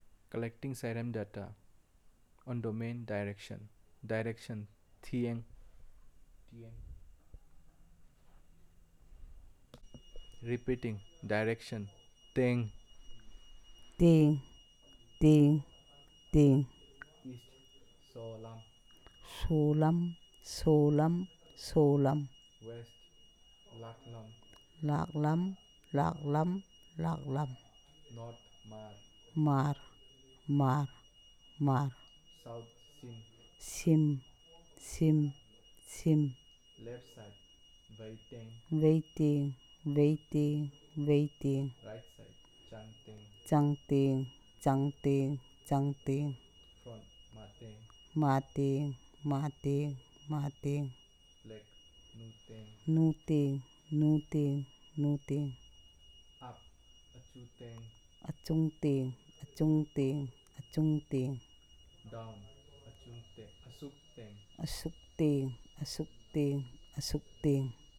dc.coverage.spatialTaranagpur
dc.description.elicitationmethodInterview method
dc.type.discoursetypeElicitation